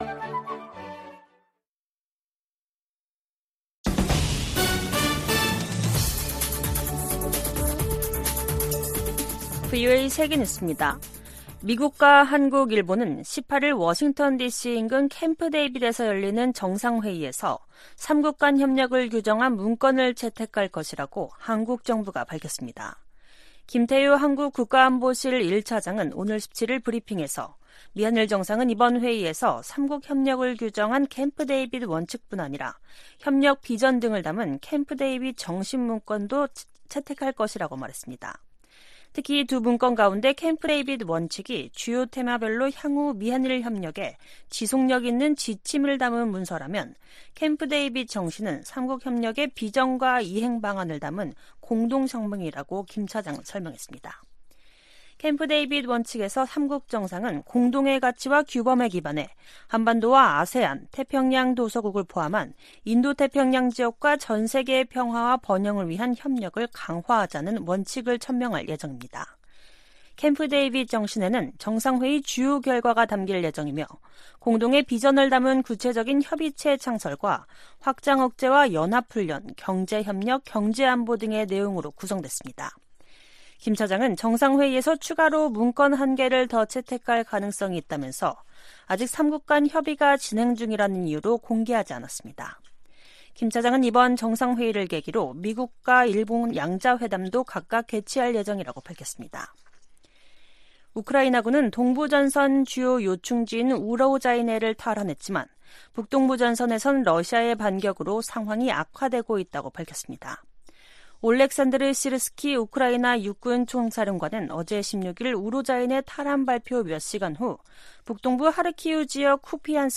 VOA 한국어 간판 뉴스 프로그램 '뉴스 투데이', 2023년 8월 17일 2부 방송입니다. 백악관은 미한일 정상회의에서 3국 협력을 강화하는 중요한 이니셔티브가 발표될 것이라고 밝혔습니다. 백악관은 자진 월북한 주한미군 병사가 망명을 원한다는 북한의 발표를 신뢰하지 않는다고 밝혔습니다. 미 재무부가 북한-러시아 간 불법 무기거래에 관해 러시아와 슬로바키아, 카자흐스탄 소재 기업 3곳을 제재했습니다.